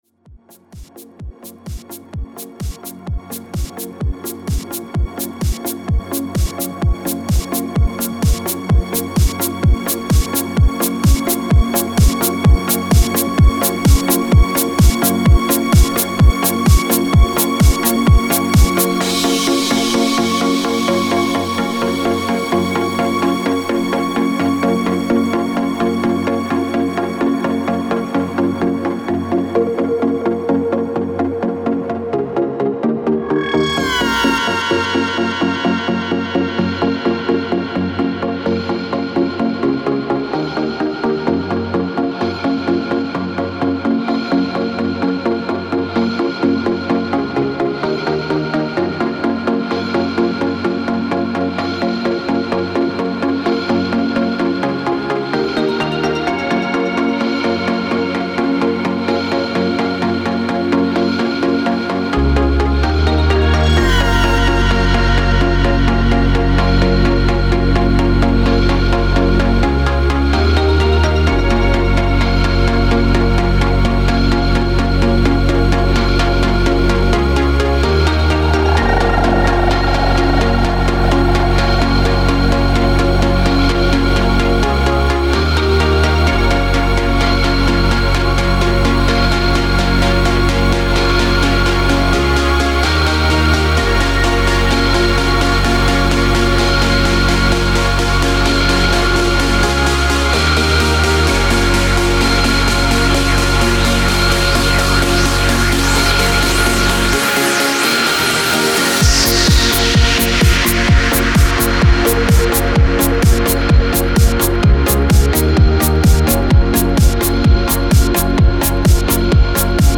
Also posted in dance